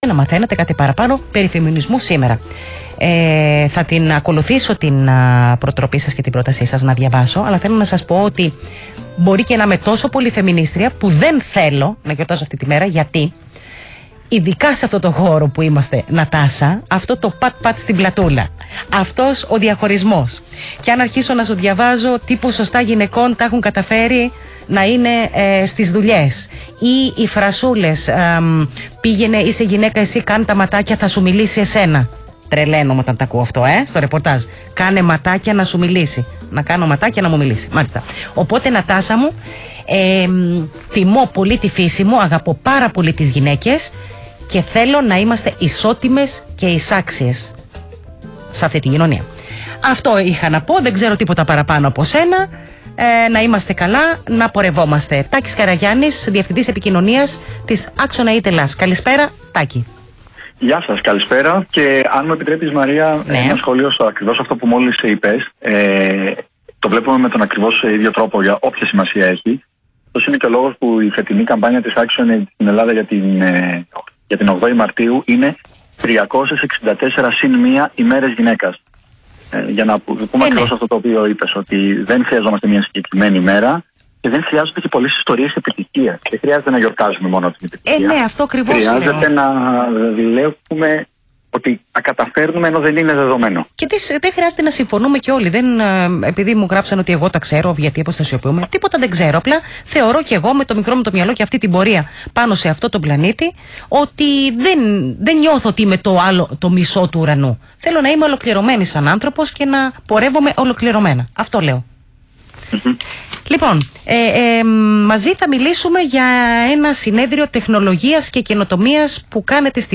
Συνέντευξη στον ΣΚΑΪ 100,3 : Πώς η τεχνολογία, η ψηφιακή πραγματικότητα και η τεχνητή νοημοσύνη επηρεάζουν τις ευάλωτες ομάδες
στη ραδιοφωνική εκπομπή